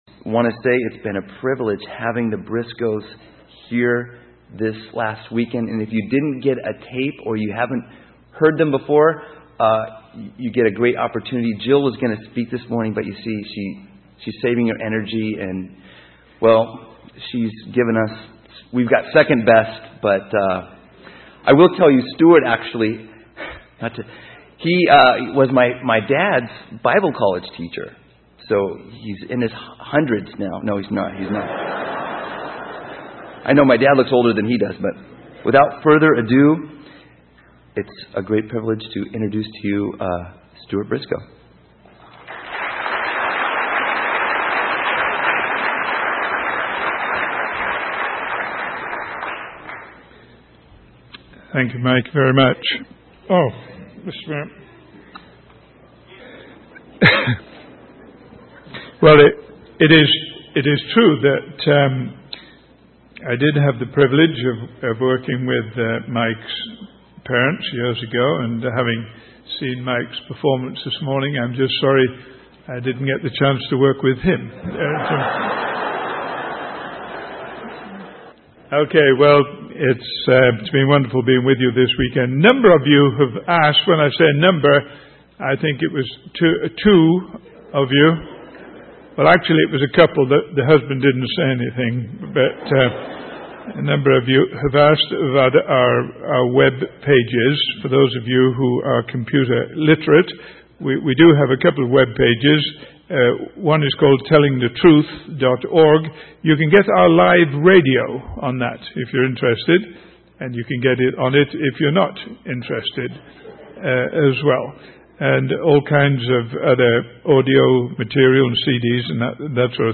In this sermon, the speaker emphasizes the importance of worship and how it is a way for humans to fully know and appreciate God.